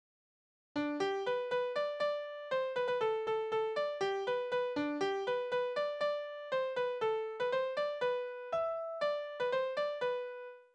Balladen: Der Tod des Fähnrichs
Tonart: G-Dur
Taktart: 2/4
Tonumfang: große None
Besetzung: vokal